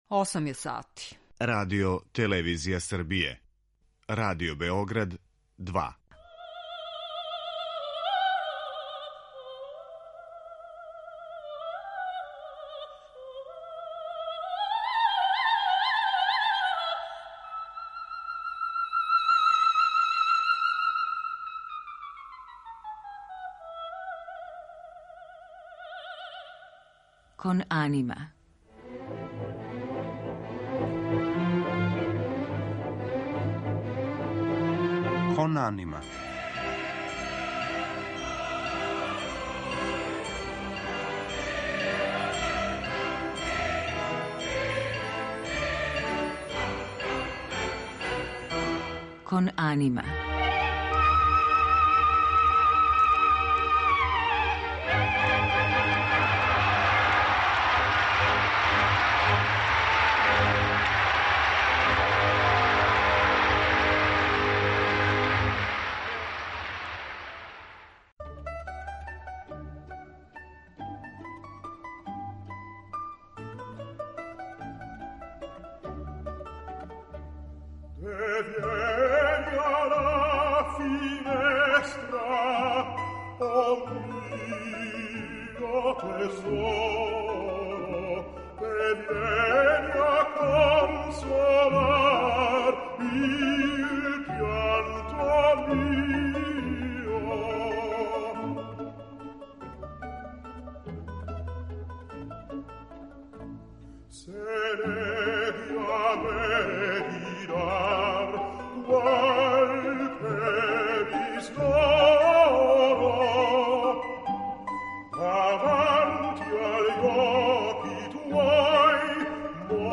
Данашњу емисију Кон анима испуниће оперске нумере које заправо представљају различите вокалне форме, специфично оперске или преузете из традиционалне музике тј. из свакодневног живота.